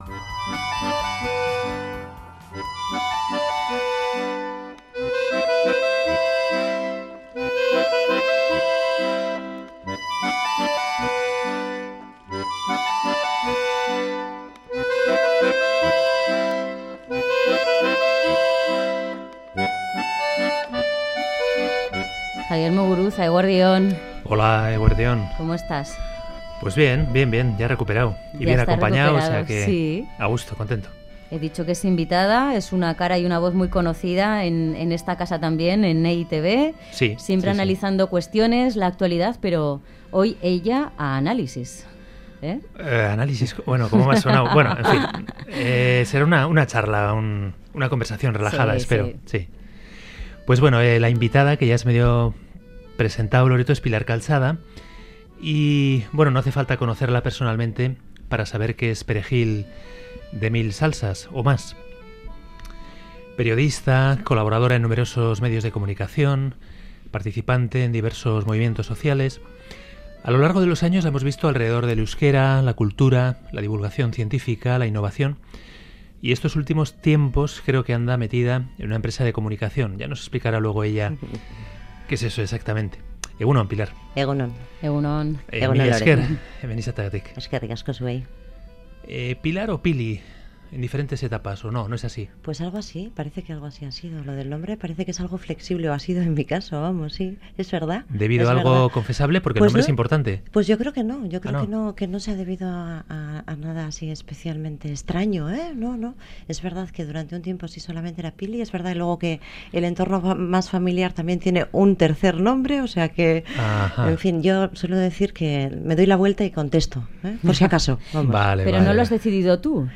La entrevista nos descubre a una mujer nacida en Donostia, con orígenes extremeños, interesada por la vida cotidiana y defensora de la competencia cooperativa.